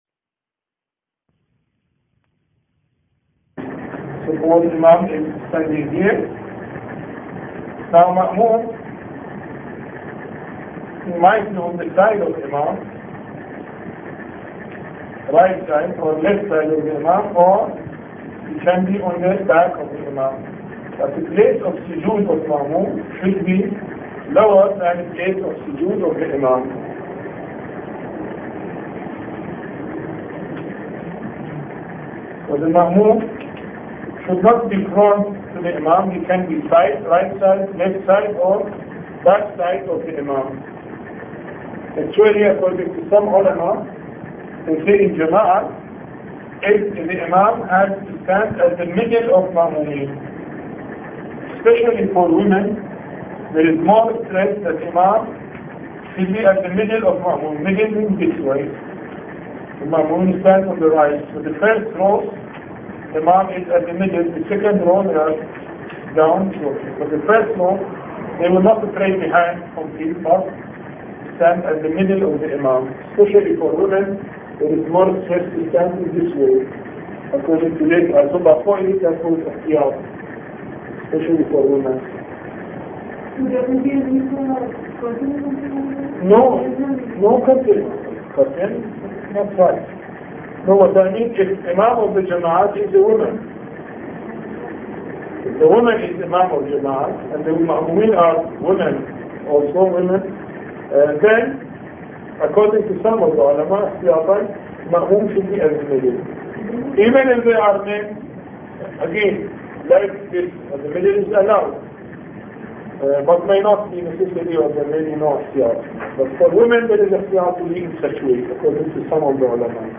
A Course on Fiqh Lecture 18